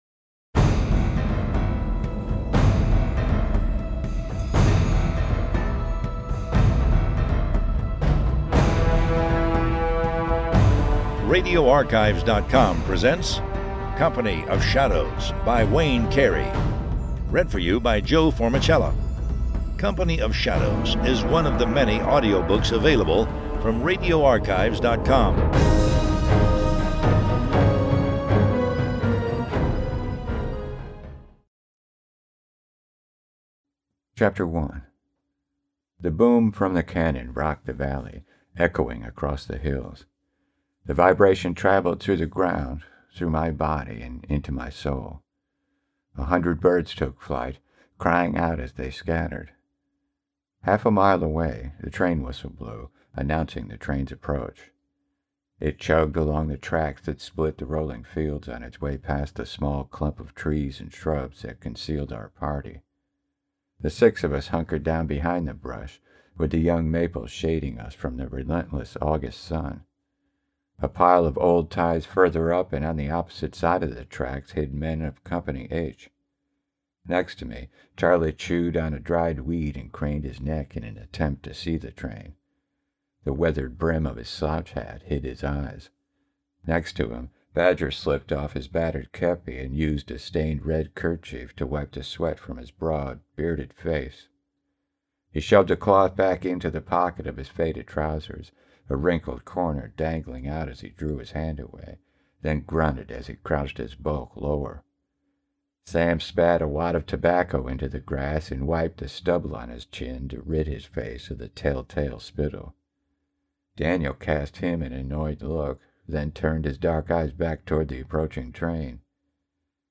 Company of Shadows by Wayne Carey Audiobook